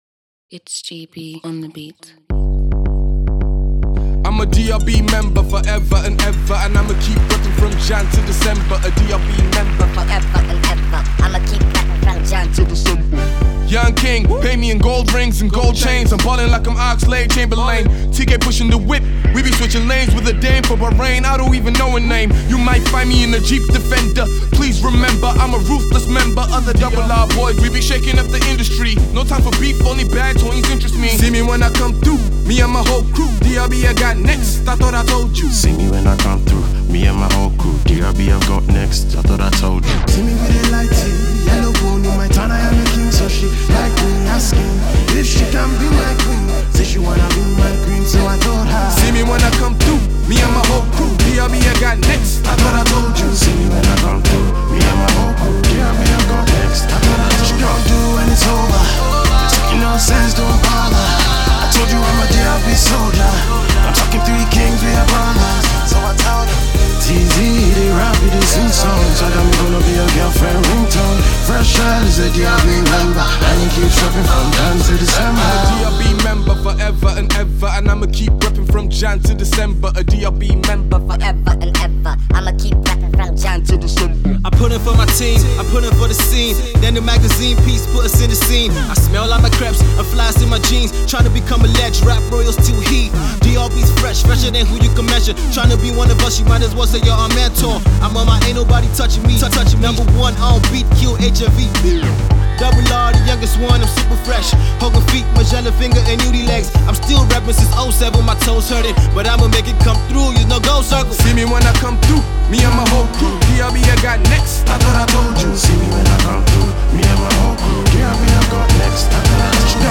the music crew is back with a banging new single for 2013.
rappers
With his rough baritone voice